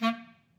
DCClar_stac_A#2_v3_rr1_sum.wav